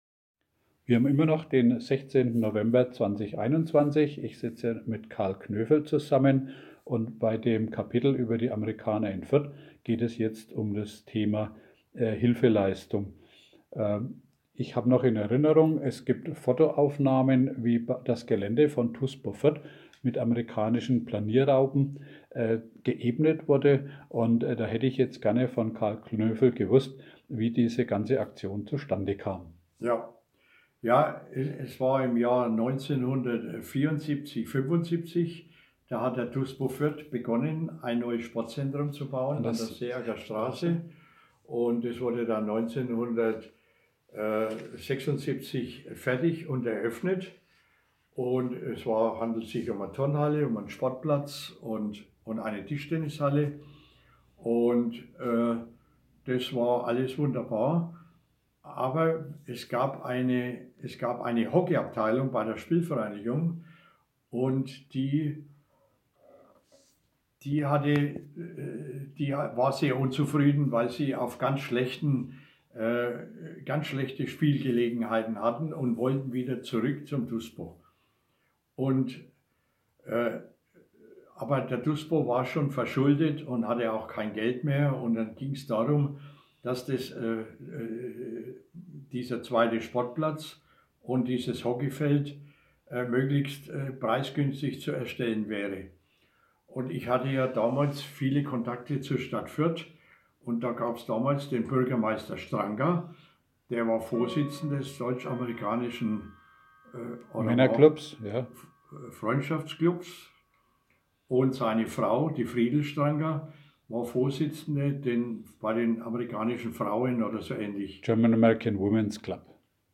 Genre Zeitzeugenberichte
Interview